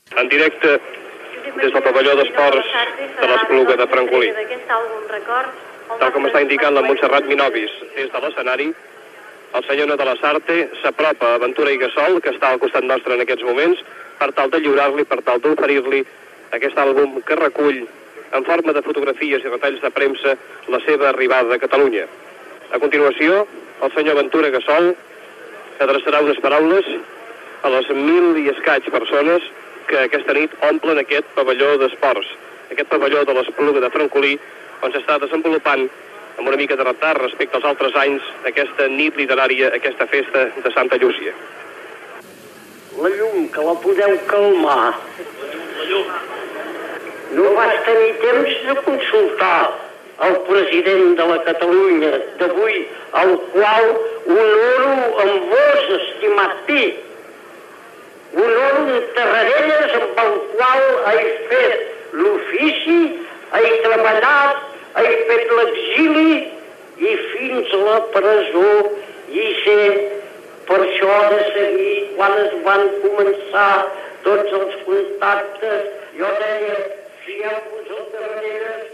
Transmissió des de l'Espluga de Francolí de la Nit de Santa Llúcia, la Festa d'Òmnium Cultural de les Lletres Catalanes
Parlament de Ventura Gassol